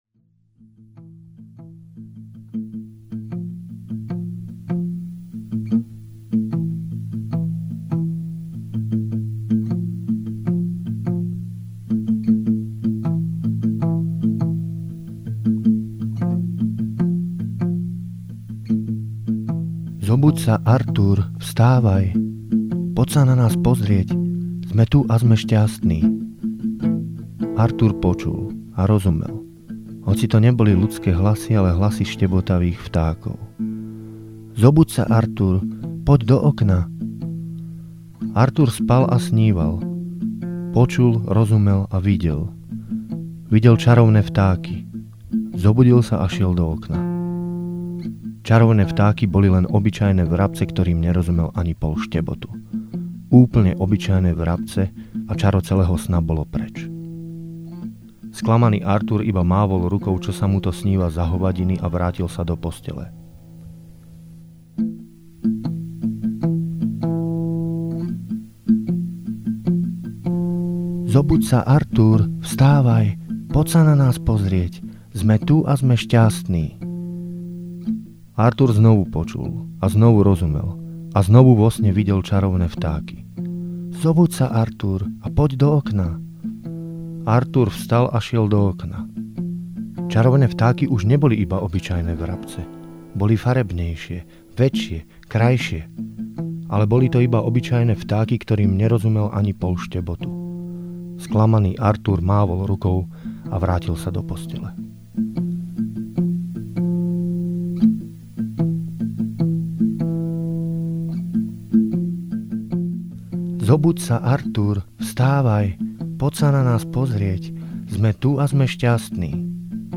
Záznam z literárnej dielne.